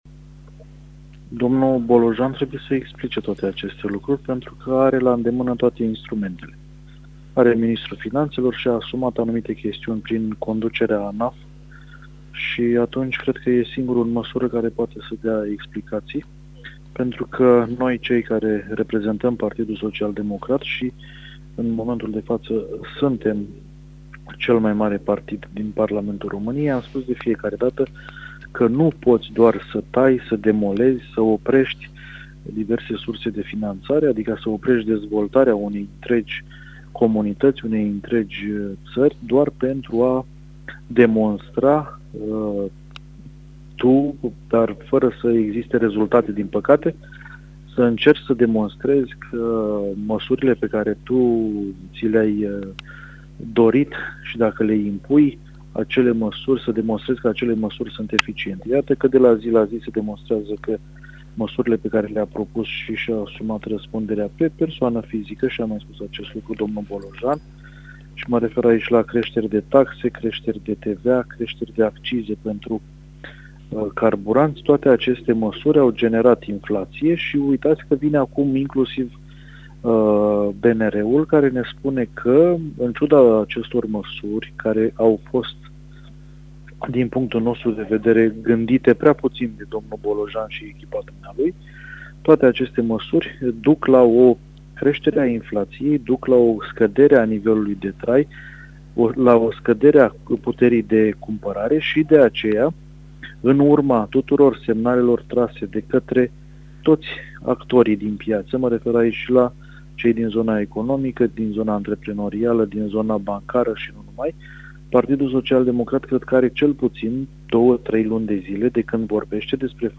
Într-un interviu amplu, acesta vorbește despre efectele „austerității selective”, despre discrepanțele dintre investițiile din Bihor și cele din Iași, dar și despre necesitatea relansării economice.